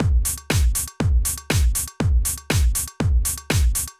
Drumloop 120bpm 01-A.wav